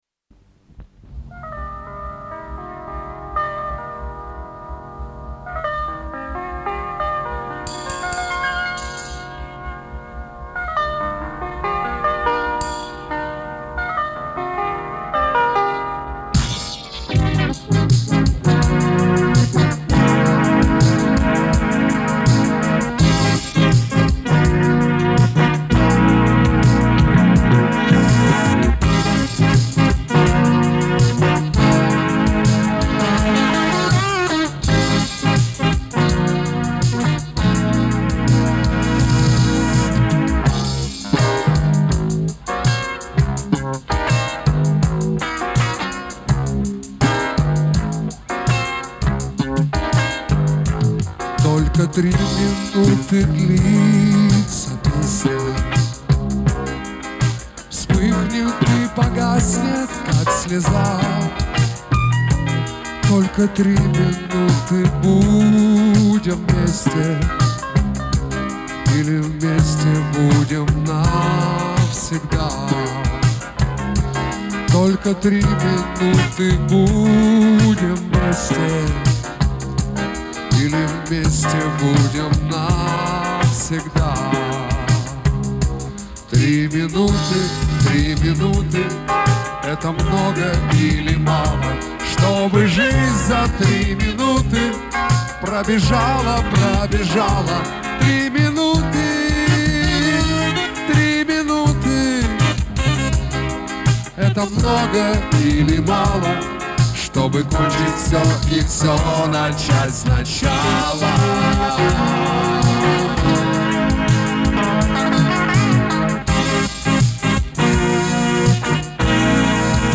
Запись с плёнки. По-моему медленно